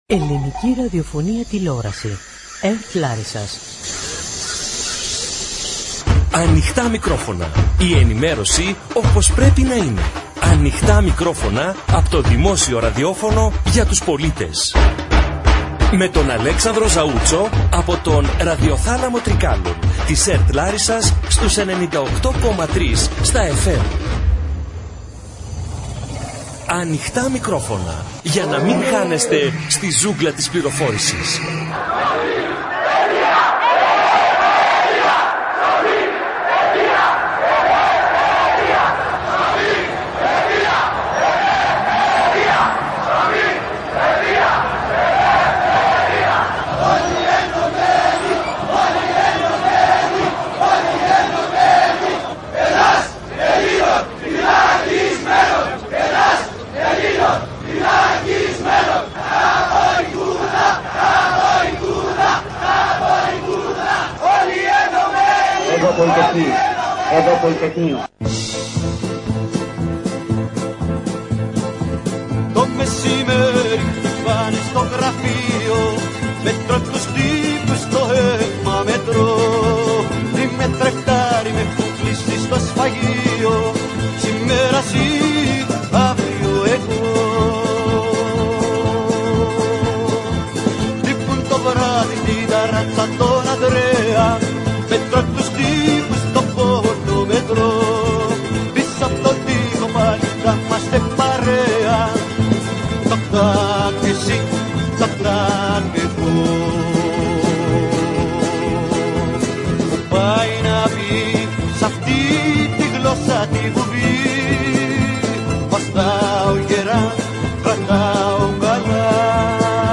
Στο αφιέρωμα για την 51η επέτειο του Πολυτεχνείου από την εκπομπή “Ανοιχτά Μικρόφωνα” (ραδιοθάλαμος Τρικάλων της ΕΡΤ Λάρισας)